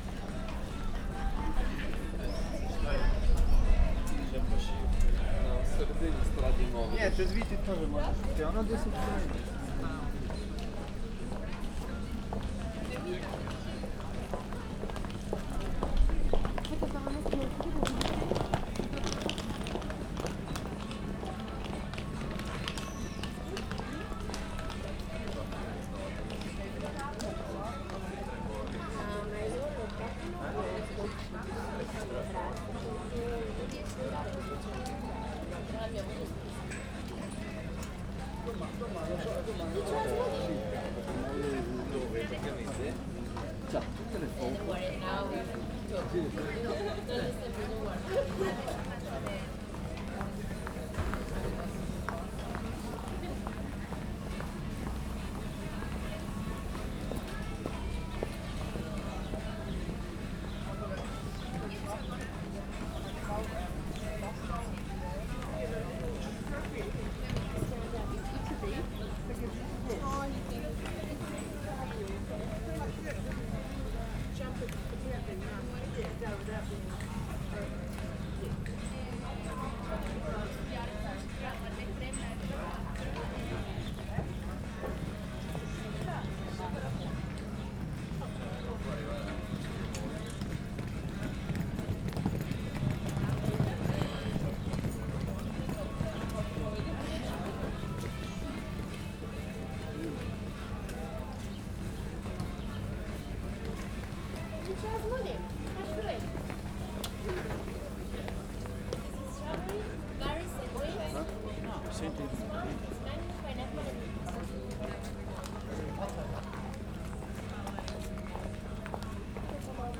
facsartgyumolcsitalbolt_kutya_velence_sds05.59.WAV